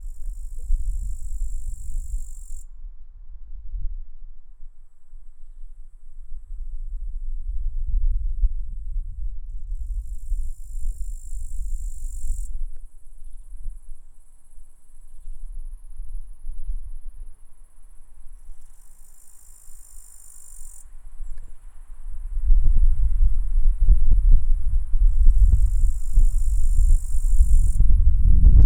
Tettigonia cantans
Feldaufnahme. Hohndorf, 09.09.2020, 22 °C. (Zoom H6, XY Mic Capsule, 96 khz, 24 bit).
Imago Männchen